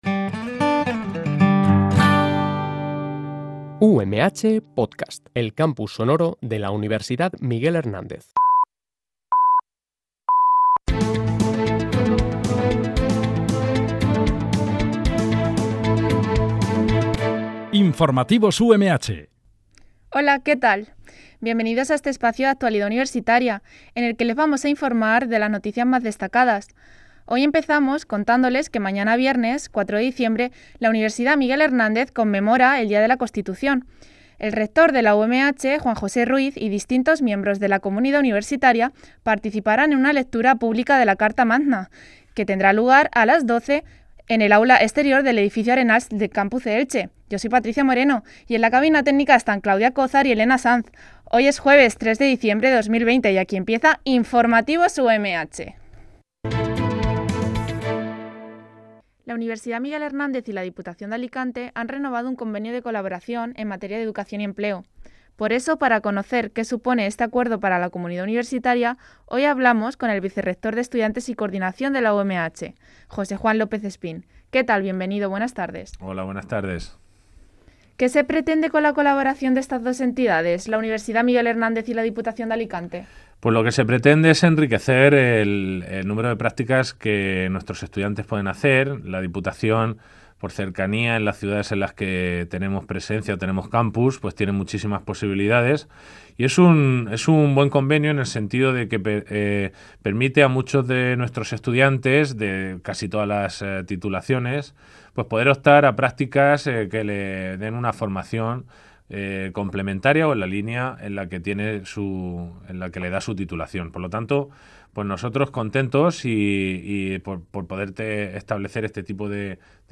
Este programa de noticias se emite de lunes a viernes, de 14.00 a 14.10 h